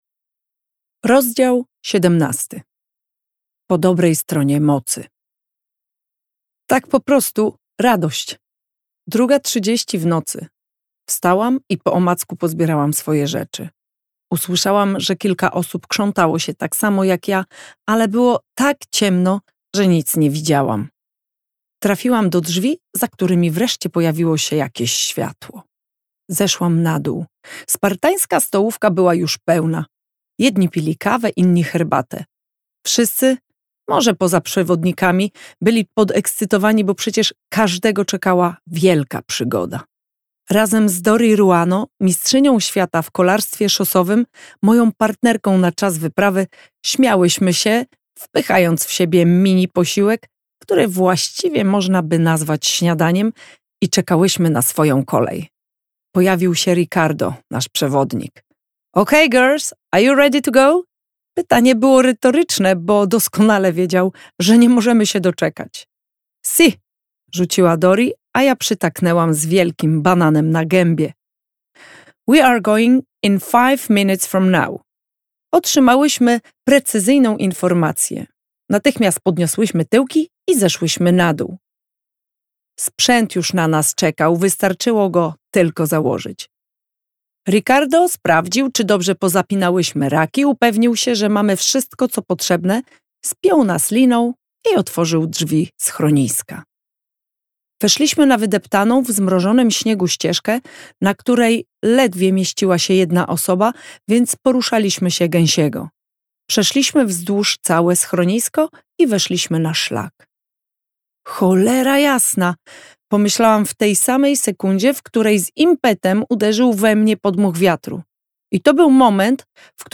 fragment książki: